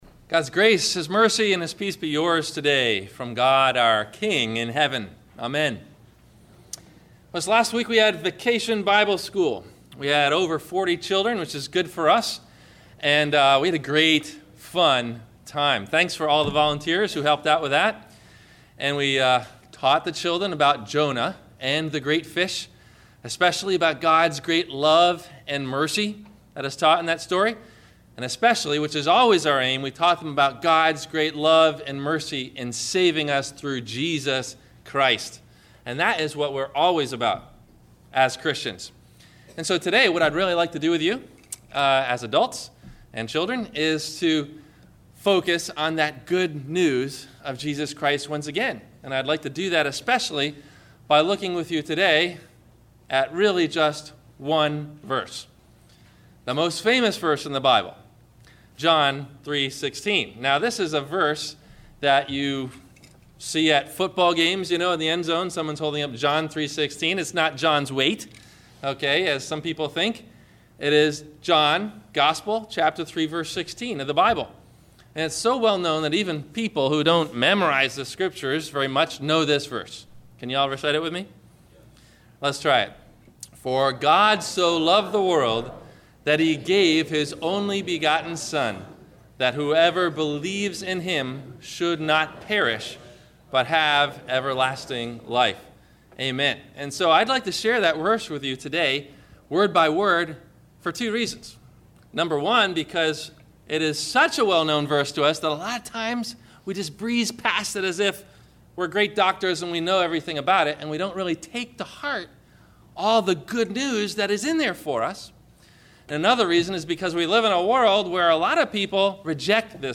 Scriptures below from the church bulletin used in the Sermon: